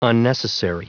Prononciation du mot unnecessary en anglais (fichier audio)
Prononciation du mot : unnecessary